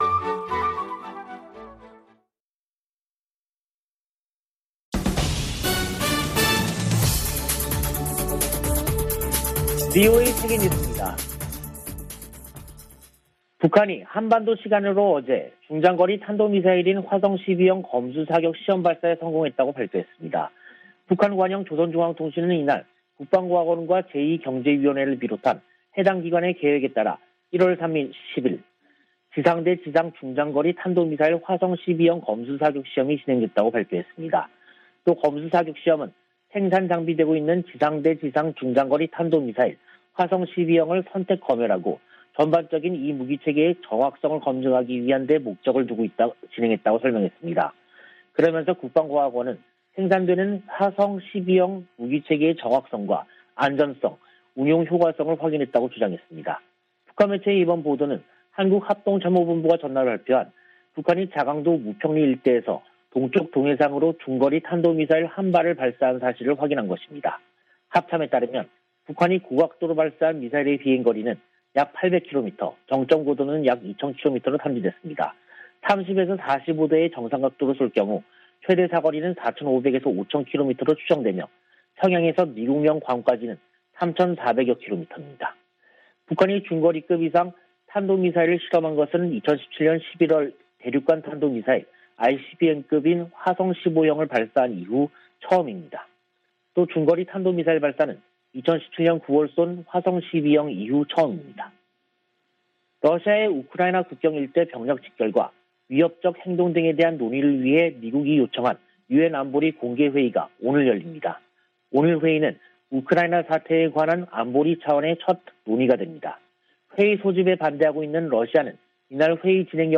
VOA 한국어 간판 뉴스 프로그램 '뉴스 투데이', 2022년 1월 31일 2부 방송입니다. 북한이 30일 발사한 미사일이 중거리 탄도미사일인 '화성-12형'이었다고 공개했습니다. 미 국무부는 4년 만에 최대 수위의 미사일 도발을 감행한 북한을 규탄했습니다.